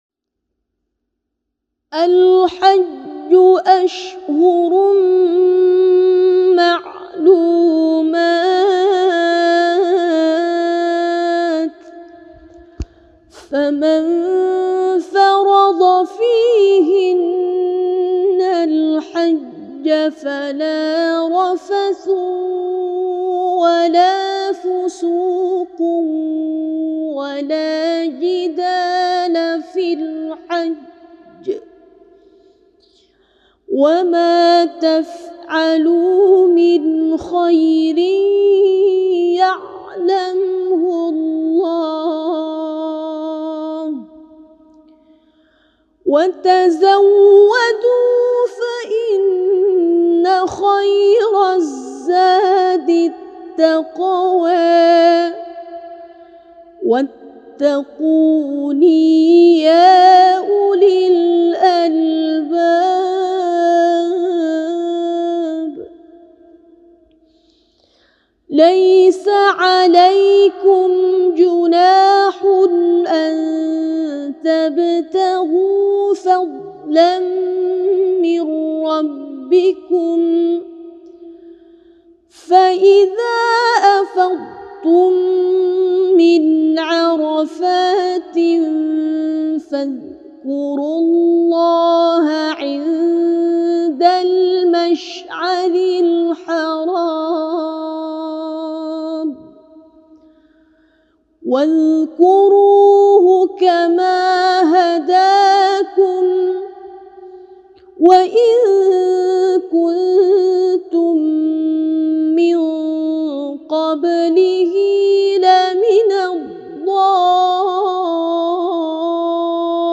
Qira’at Imam Abu Amru Riwayat As-Susi